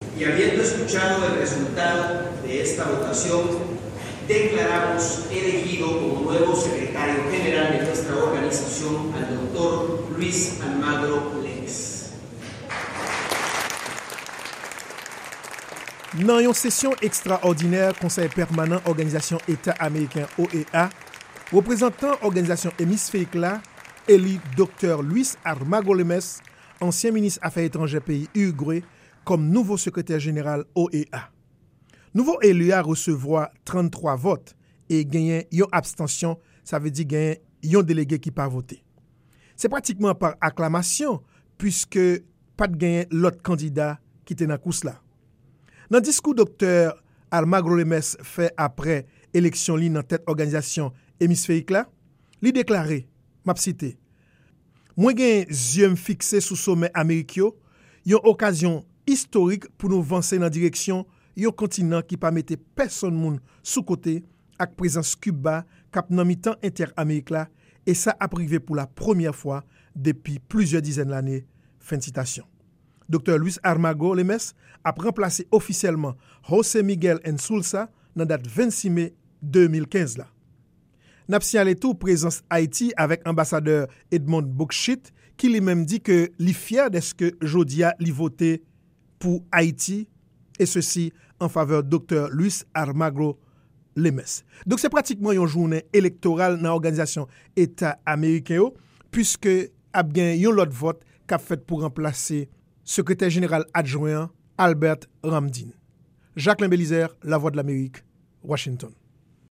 repòtaj